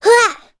Gremory-Vox_Attack1.wav